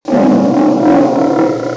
Le lion | Université populaire de la biosphère
lion_3.mp3